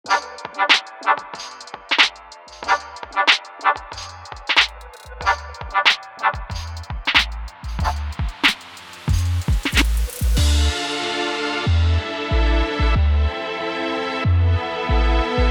Radio Effekt "aufziehen"
Gerade für Introgeschichten oder Brücken zum Refrain sehr cool...dann geht es quasi vom blechernen Küchenradio in Mono (auf Wunsch mit Störartefakten) in den hochdefinierten und breiten Refrain.
Ein Beispiel aus einem aktuellen Projekt; ist noch ein frühes Stadium und nicht ausproduziert.